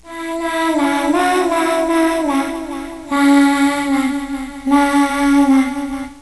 Escucha a la niña
La niña que cantaba es uno de los que más gustó.
07-lalalalala.wav